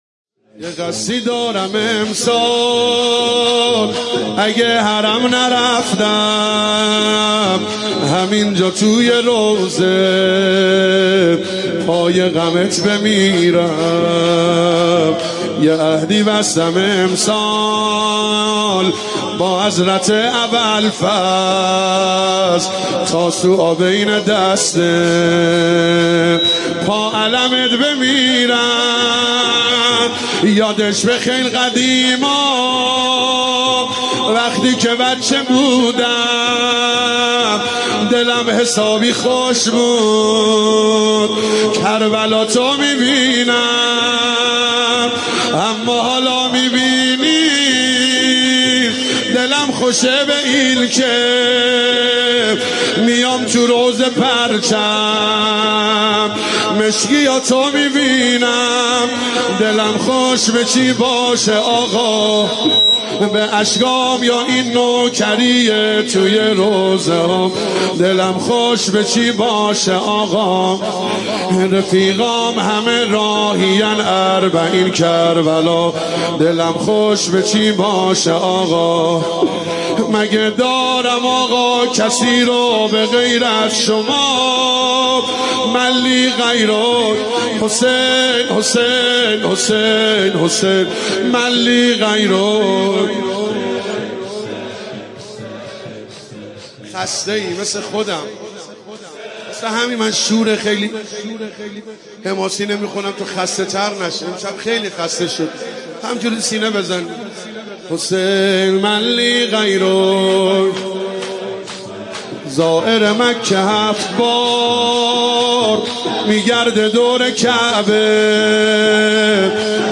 مداحی اربعین
شب هشتم محرم